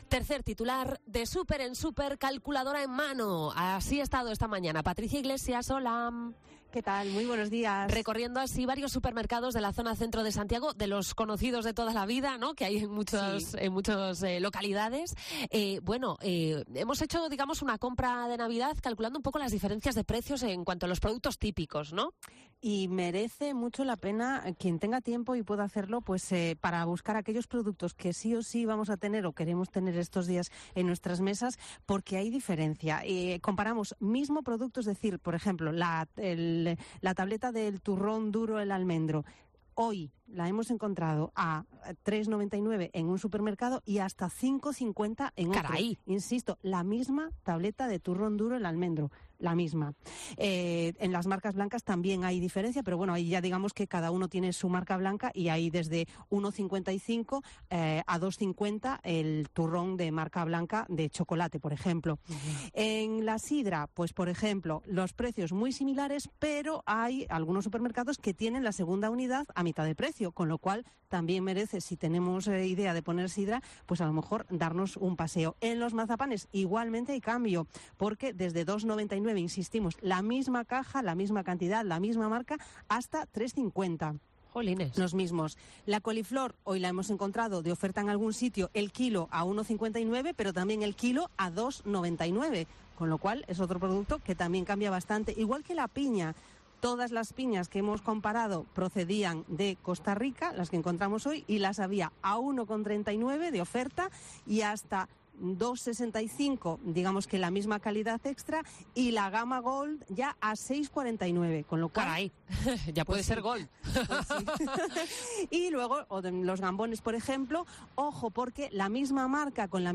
COPE Santiago se va de supermercados en el ensanche de Santiago, calculadora en mano.
Nos encontramos ciudadanos que confiesan que adelantan compras o que tienen ya el marisco congelado.